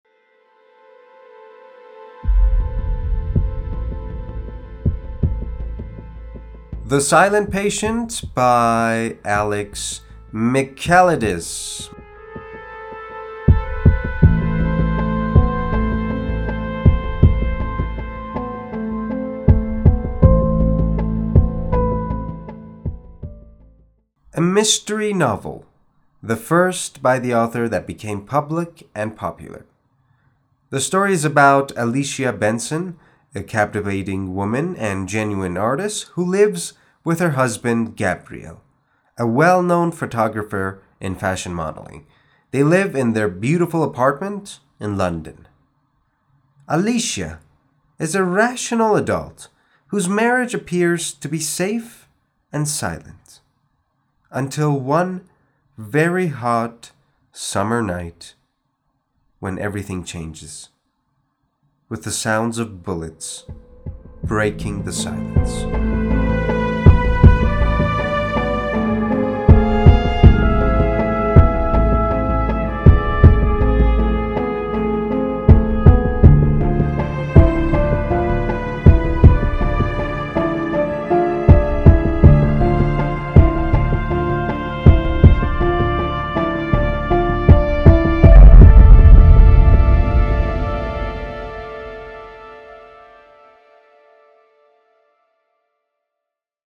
معرفی صوتی کتاب The Silent Patient